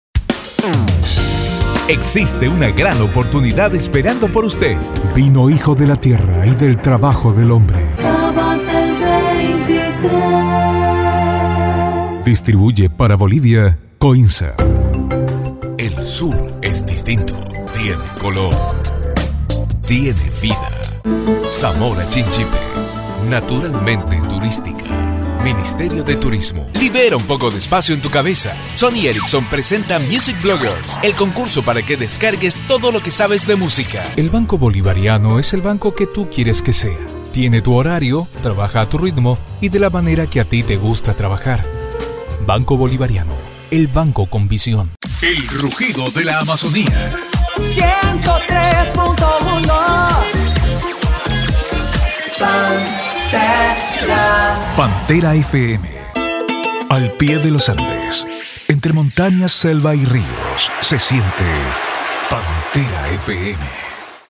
Adult male voice, warm and trustworthy, with clear diction and strong on-mic presence.
Television Spots
Spanish Neutro